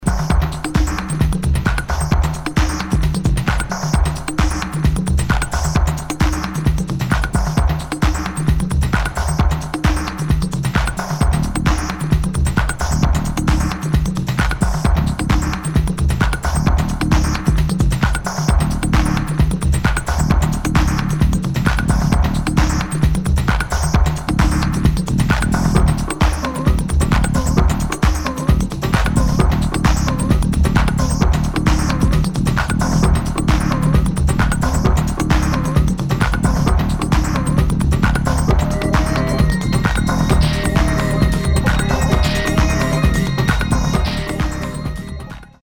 [ TECHNO / ACID / TRANCE ]